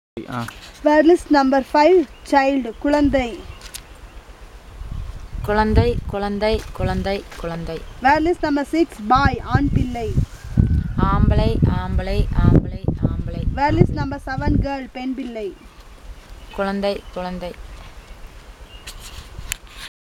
Elicitation of words about stages of life - Part 2